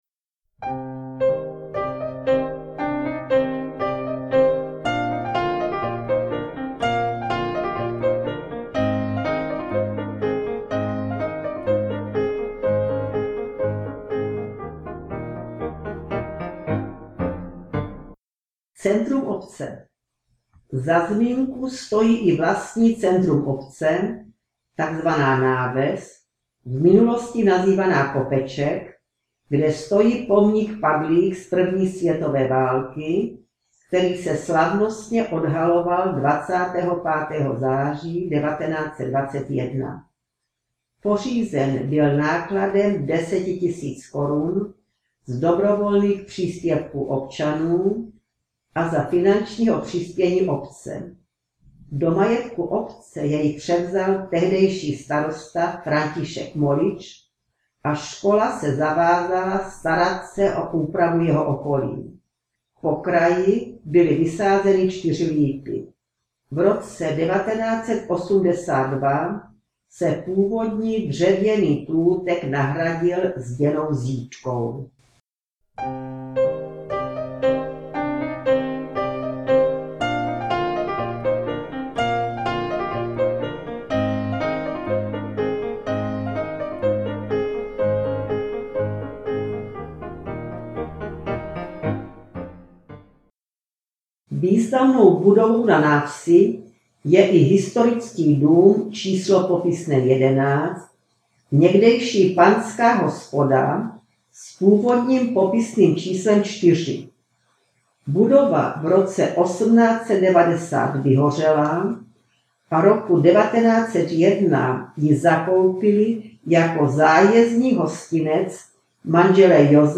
Zajímavý text s obrázky doplňuje mluvené slovo s hudbou.